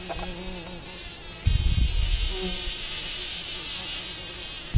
Abb. 05: Hummel in Rosenblüte, häufig schlagen die Flügel gegen die Blütenblätter.
Abb. 06: Hummelflügel in Rosenblättern, das tiefe Brummen zwischen 0 und 1 Sekunde, sowie zwischen 2 und 2,5 Sekunde, dazwischen höhere Frequenzen (sehr viele Obertöne) beim Schlagen der Flügel gegen die Blätter.